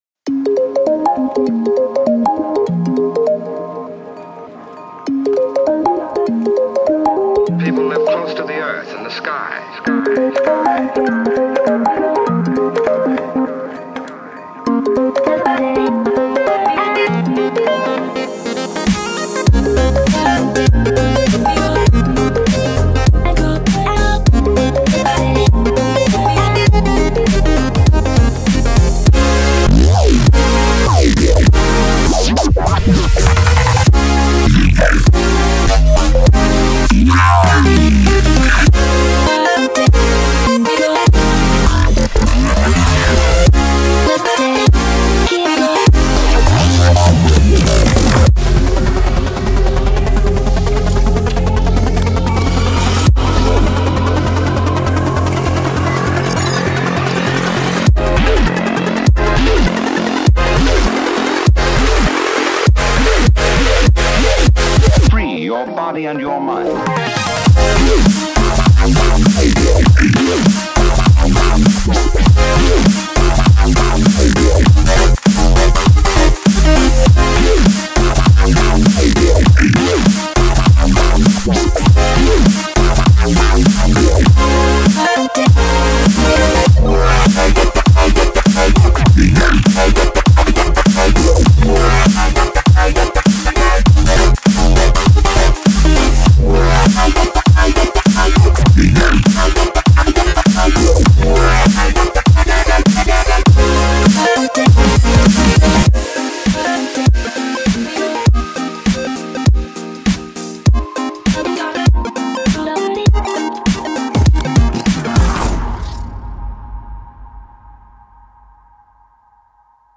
BPM100
Comments[GLITCH HOP]